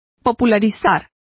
Complete with pronunciation of the translation of popularize.